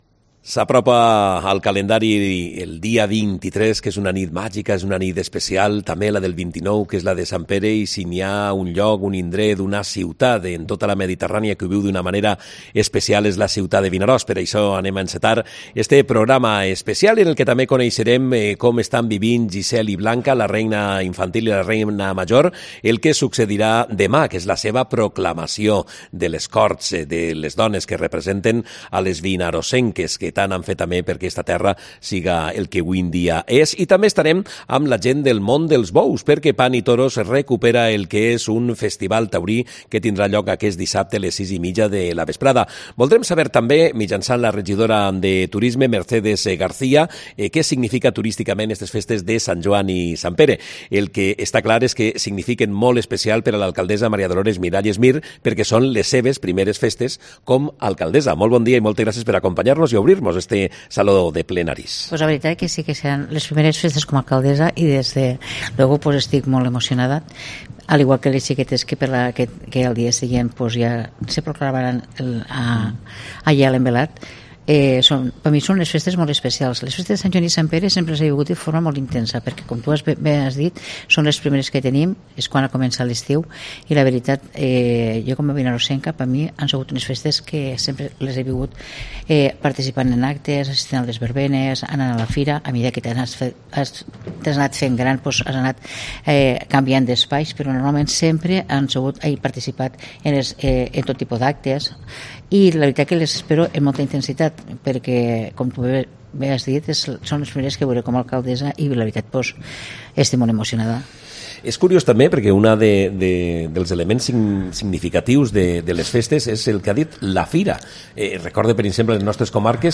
Mª Dolores Miralles, alcaldesa de Vinaròs, habla de las fiestas locales con Víctor García
Nos habla también de la decisión de contar con Charo Miralles, ex-alcaldesa de Benicarló, como mantenedora de las fiestas locales. Victor García, el nuevo concejal de las fiestas, nos cuenta los cambios necesarios que se han llevado a cabo en esta nueva edición de las fiestas populares.